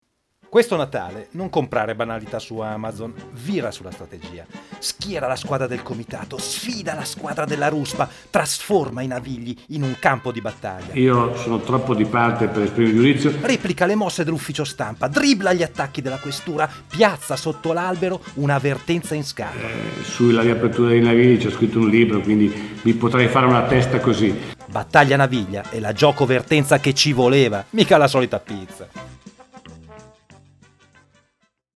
LO SPOT RADIOFONICO